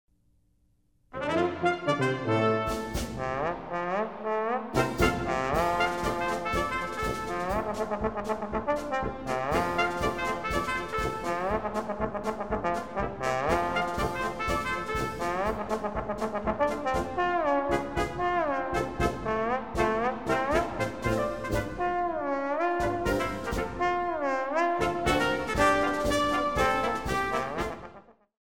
With the slide, trombonists are able to produce interesting sound effects.
trombone1.mp3